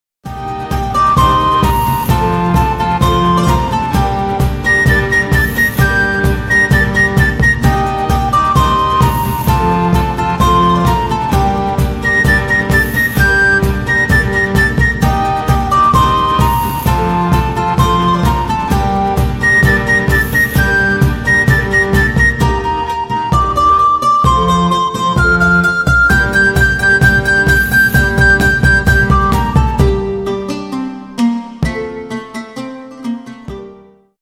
Ремикс
тихие